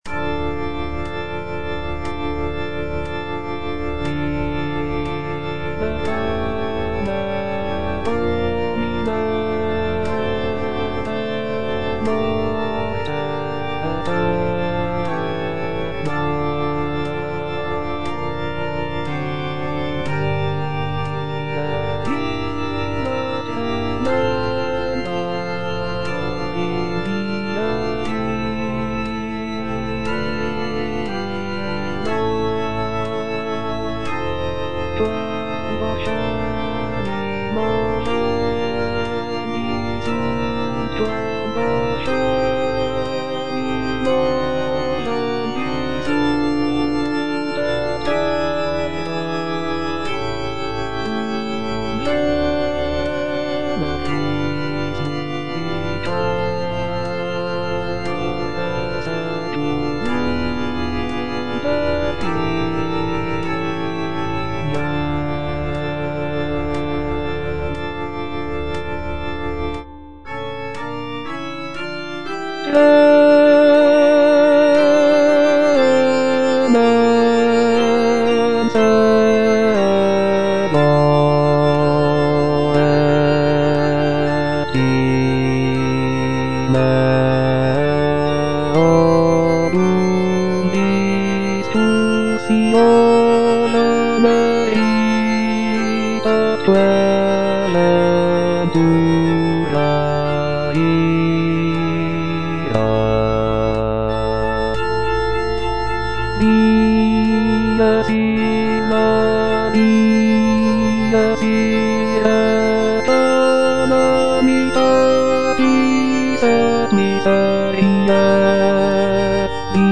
G. FAURÉ - REQUIEM OP.48 (VERSION WITH A SMALLER ORCHESTRA) Libera me (bass I) (Voice with metronome) Ads stop: